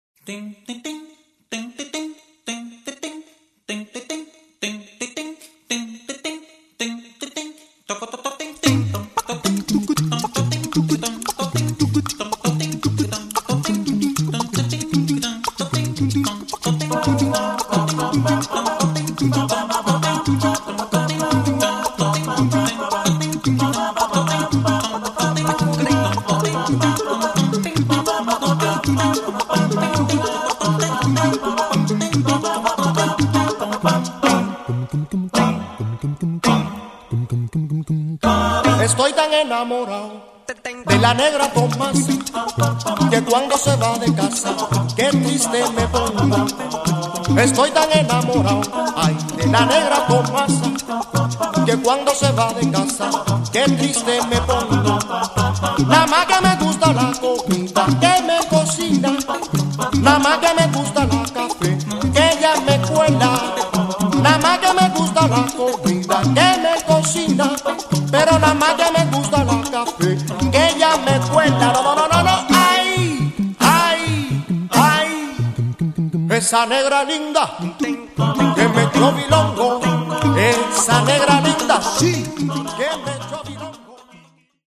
Cubani.
Le percussioni infatti sono sbalorditive.